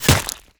bullet_impact_ice_05.wav